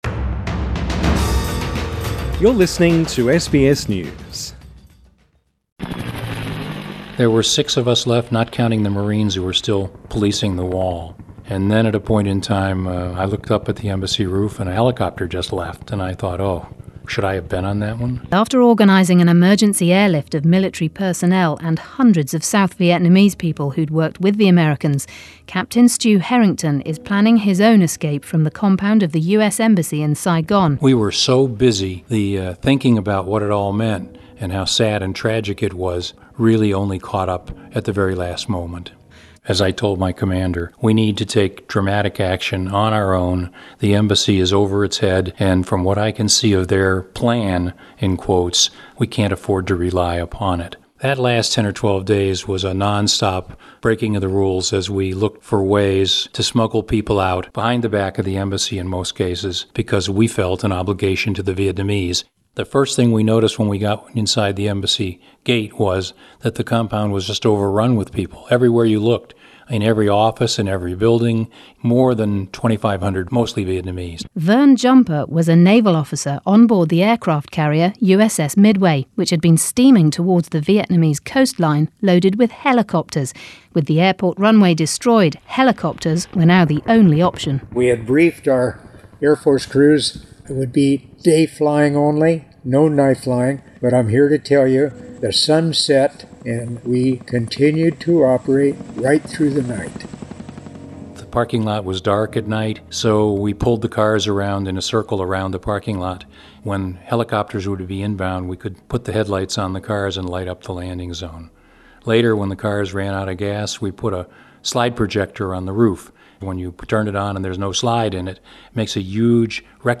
Two Americans who were in Saigon spoke about the chaos and how they bent and broke the rules to get as many people out as they could.